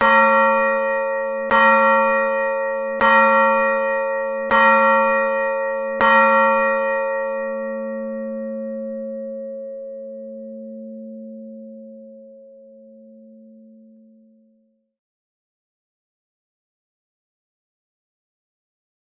Ιδανική Κρούση
Ρυθμίζοντας τους μηχανισμούς επιτυγχάνουμε τη σωστή κωδωνοκρουσία με τη μέγιστη ένταση και διαύγεια στην χροιά της καμπάνας χωρίς να καταπονείται το τοίχωμα της από την δύναμη της κρούσης.
idealbellringing.mp3